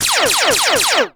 EnemyLasers2.wav